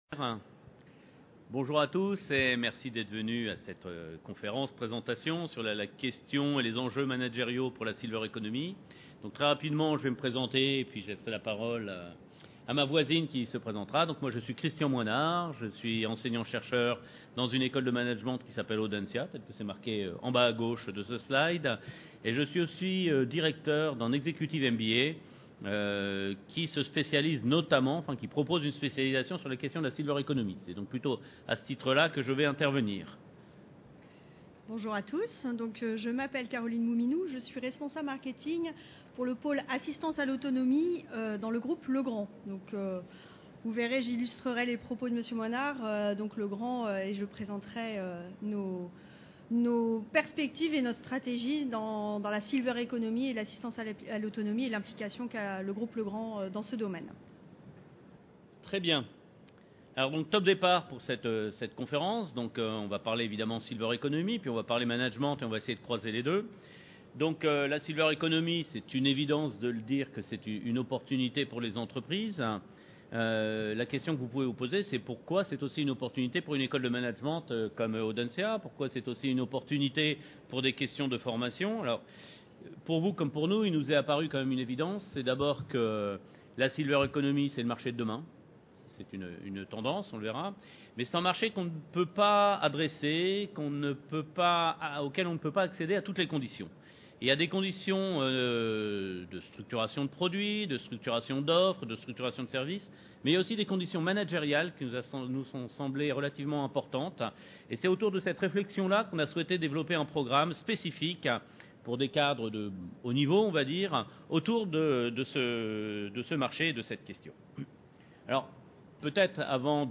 Quelles compétences managériales pour réussir le tournant de la Silver Economie ? Conférence Silver Economy Expo 2014